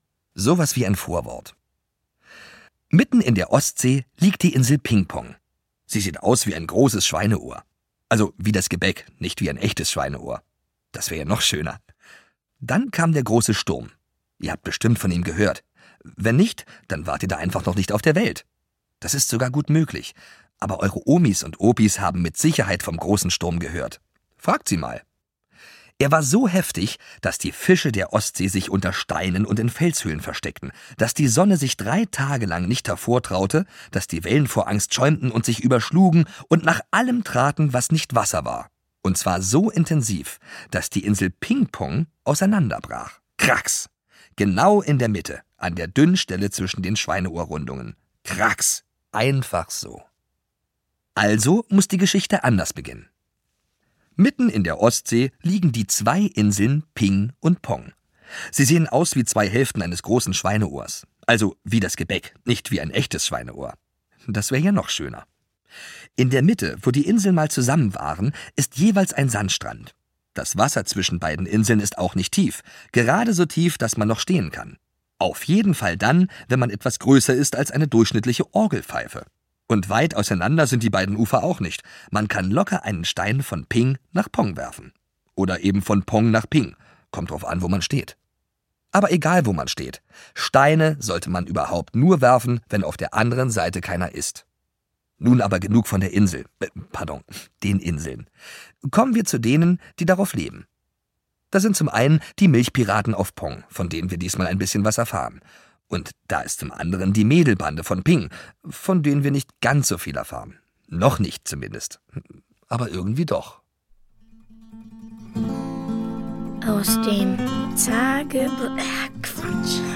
Reihe/Serie DAV Lesung für Kinder